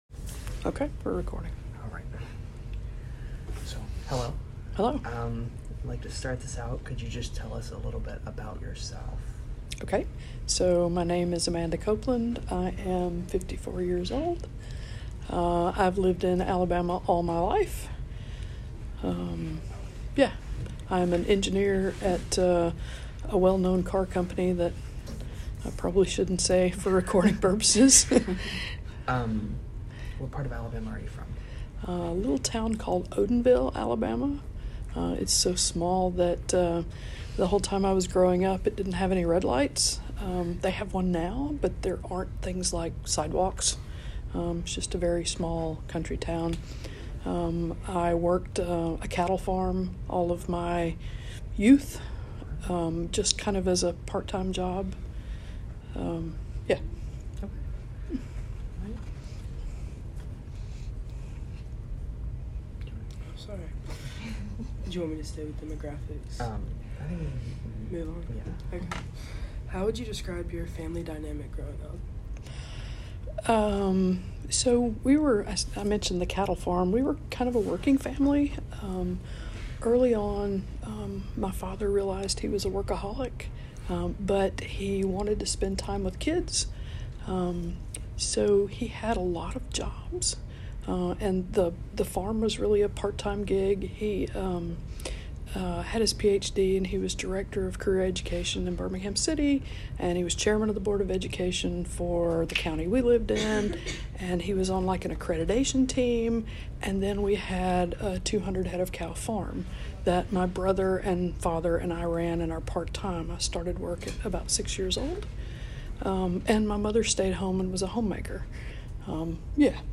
Oral History
It was conducted on November 14, 2022 at the Gorgas Library on the University of Alabama campus. The interview concerns her experience growing up and living in the south as a lesbian.